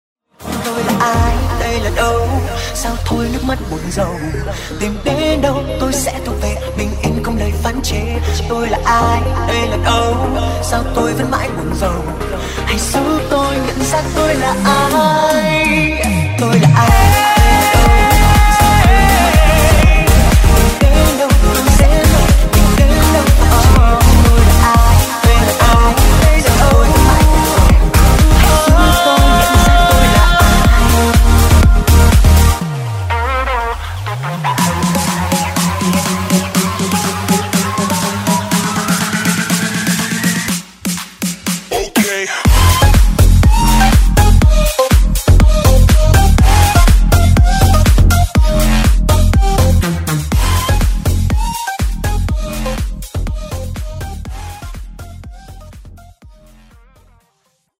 G House Version